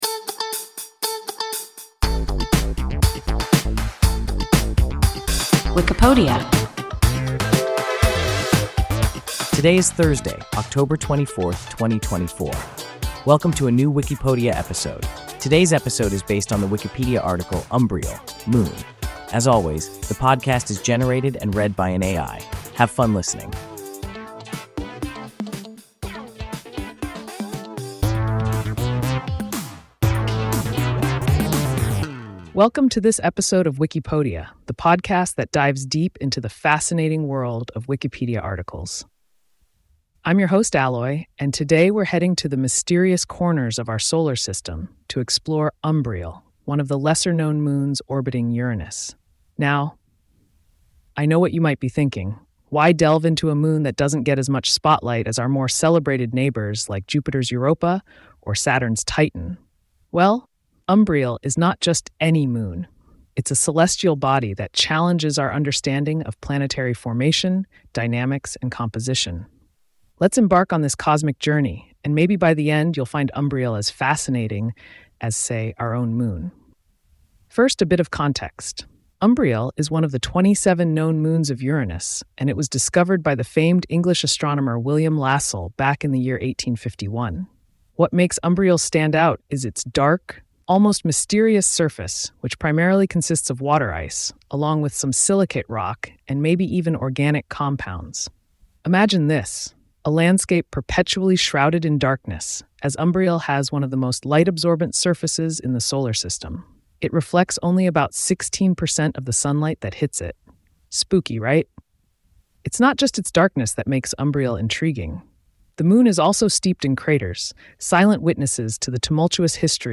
Umbriel (moon) – WIKIPODIA – ein KI Podcast